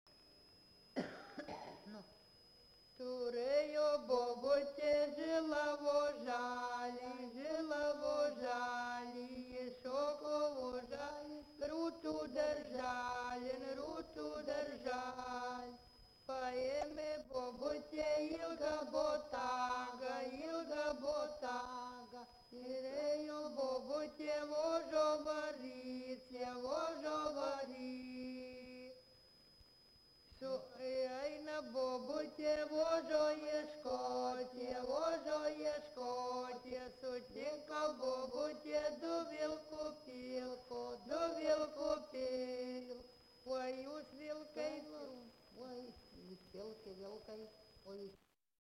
Subject daina
Erdvinė aprėptis Viečiūnai
Atlikimo pubūdis vokalinis